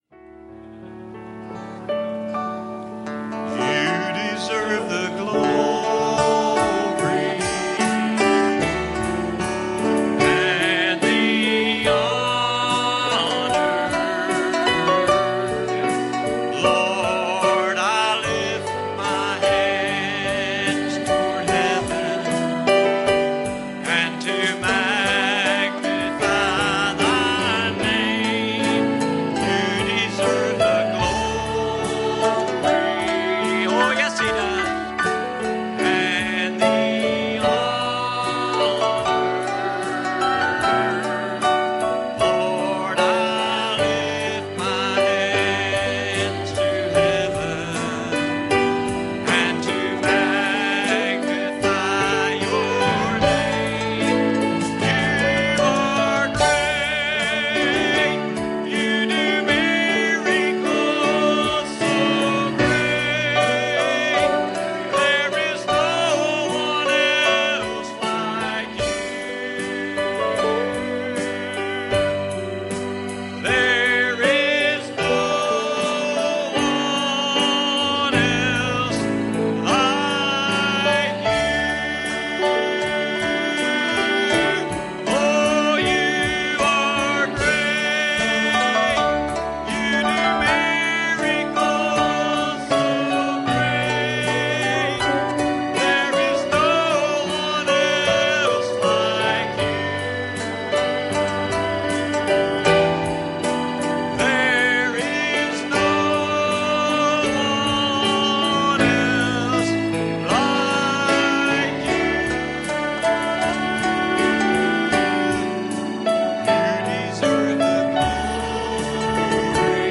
Passage: Matthew 27:41 Service Type: Wednesday Evening